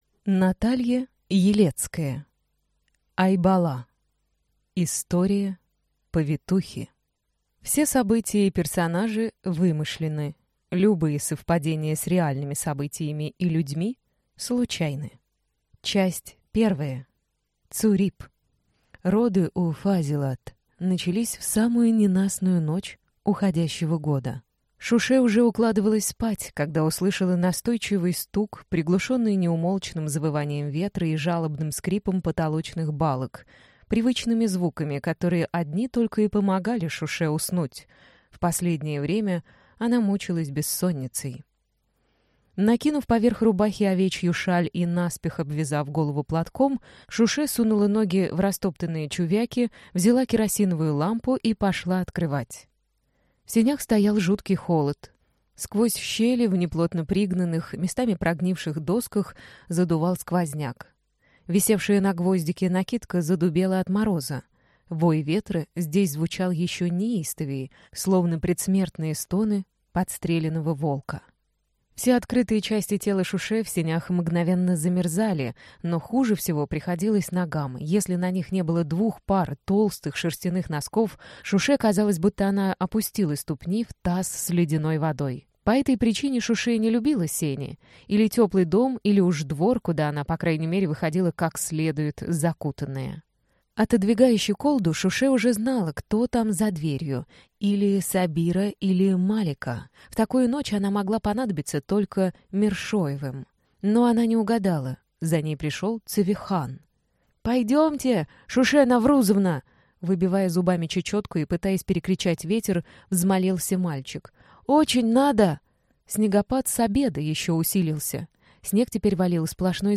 Аудиокнига Айбала. История повитухи | Библиотека аудиокниг